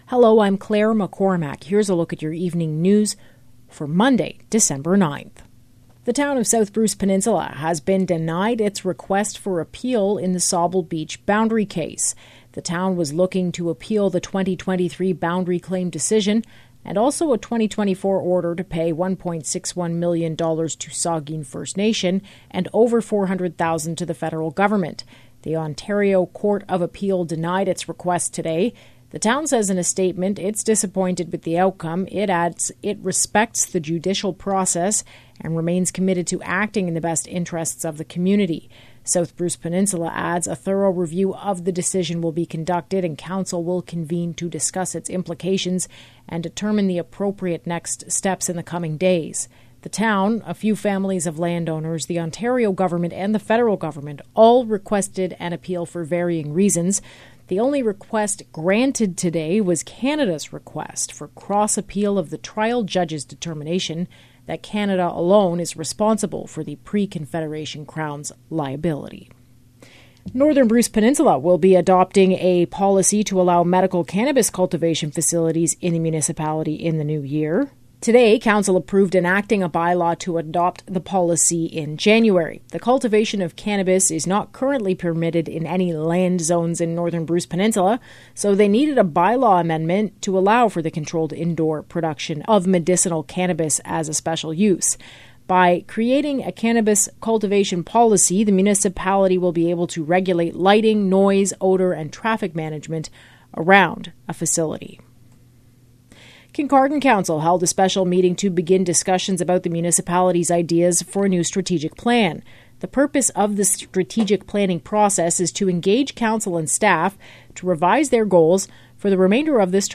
Evening News – Monday, December 9th